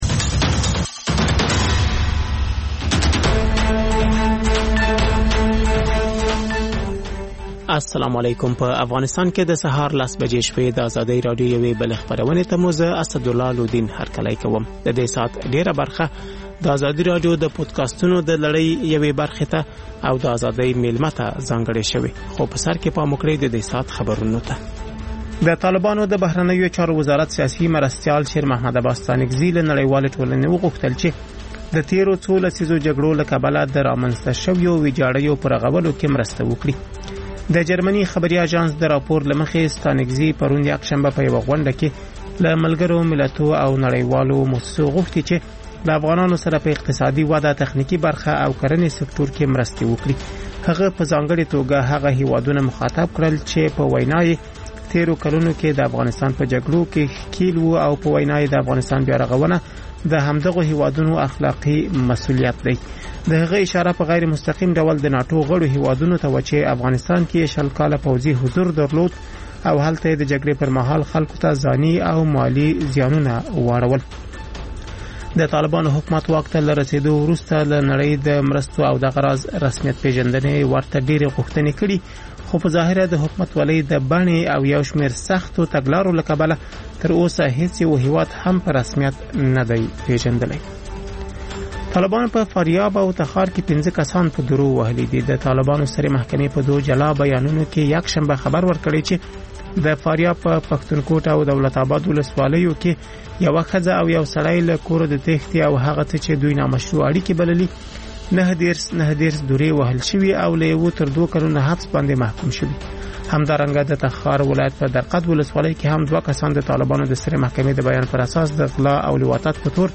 لنډ خبرونه